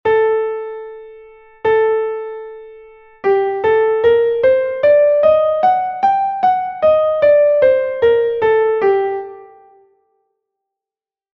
escala_sol_menor_con_la.mp3